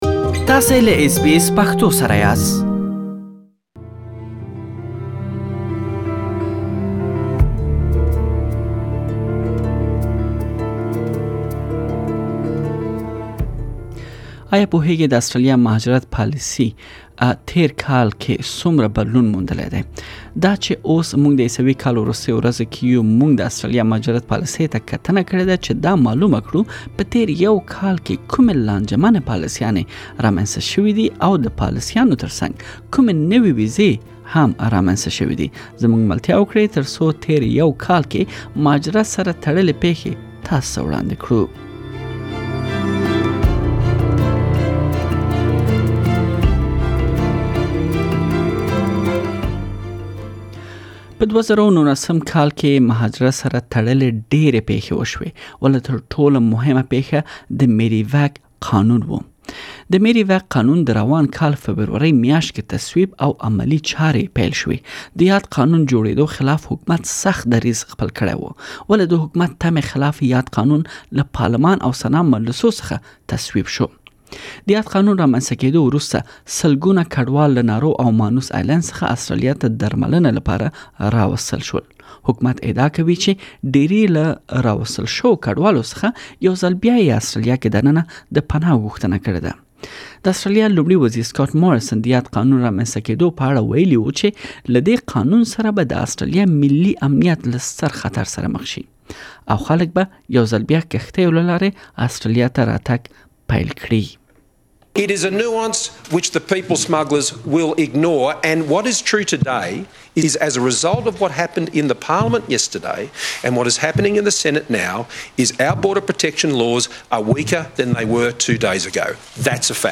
پدې رپوټ کې به تاسې پدې پوه شئ چې په تير يو کال کې د اسټراليا مهاجرت سره تړلې کومې لانجمنې پاليسيانې رامينځته شوې او يادو پاليسانو ترڅنګ کومې نوې ويزې رامينځته شوې؟ بشپړ تيار شوی رپوت واورئ.